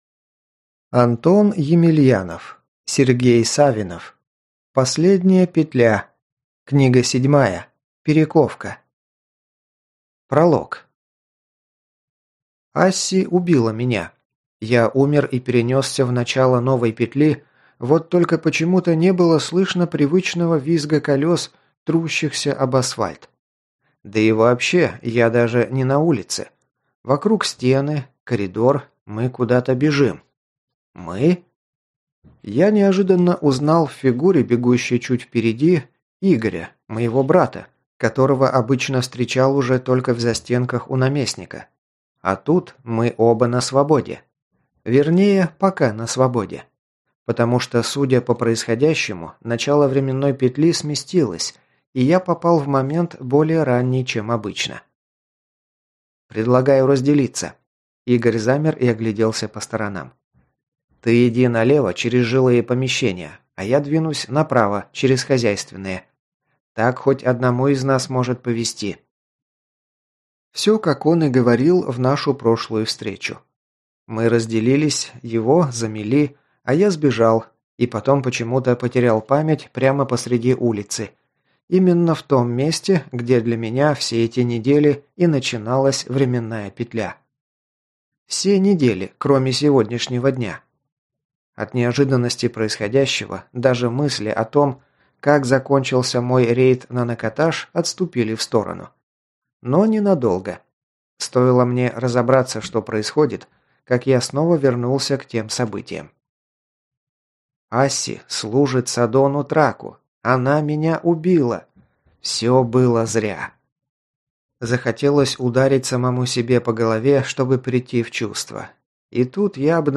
Аудиокнига Последняя петля. Книга 7. Перековка | Библиотека аудиокниг